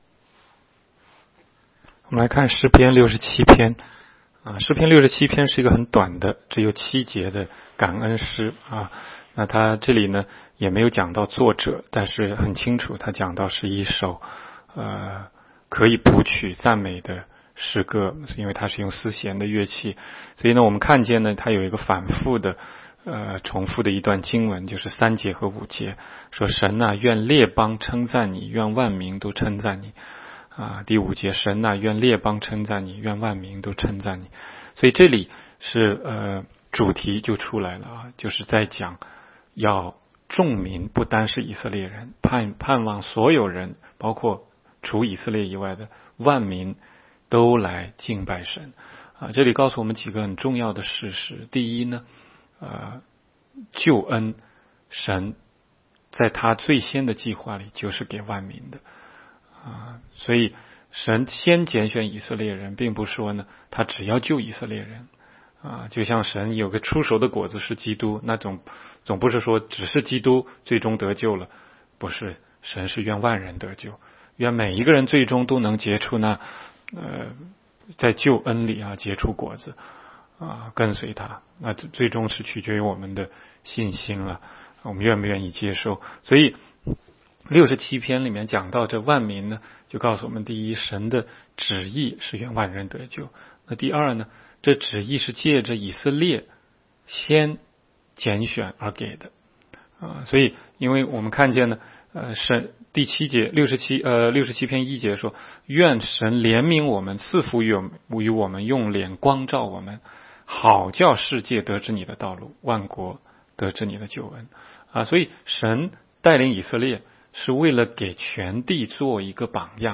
16街讲道录音 - 每日读经-《诗篇》67章